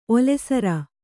♪ olesara